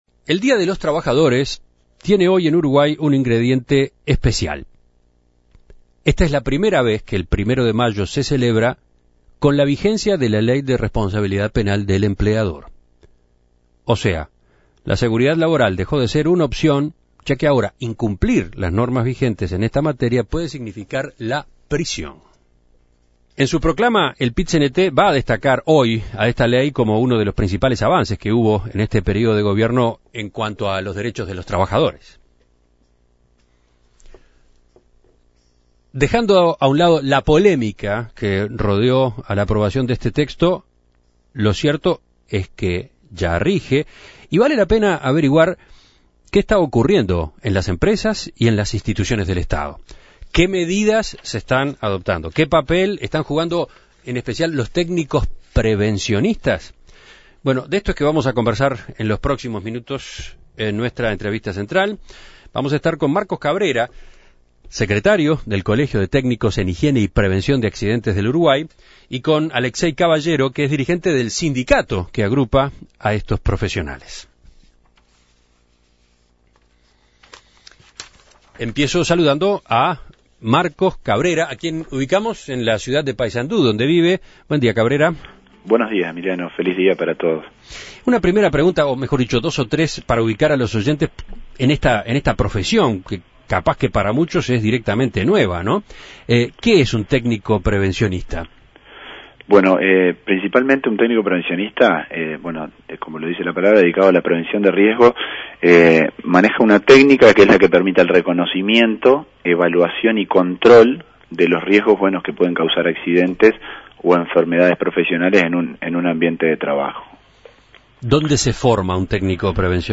En una nueva celebración del Día de los Trabajadores, uno de los temas vigentes es la aplicación de la nueva ley de Responsabilida Penal Empresarial. Desde su aprobación hace más de un mes, ha crecido la preocupación por parte de los empleadores en materia de seguridad laboral. En este marco, En Perspectiva entrevistó